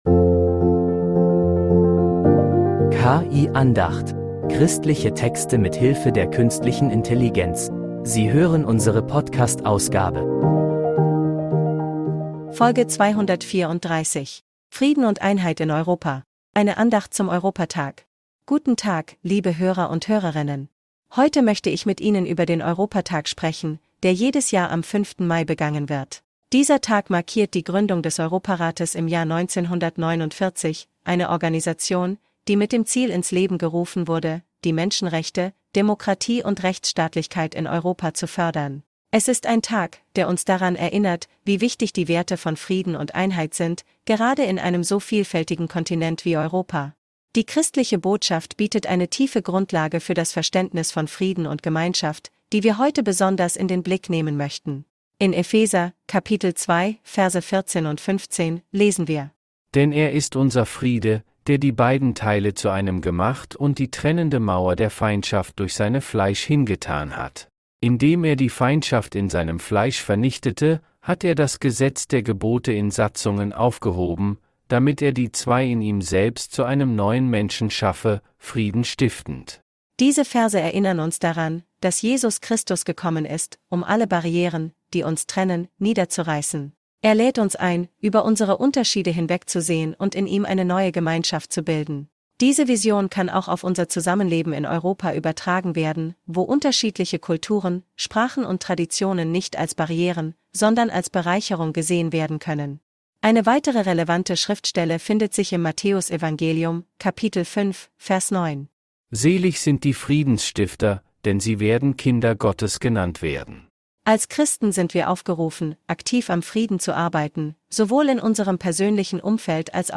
Eine Andacht zum Europatag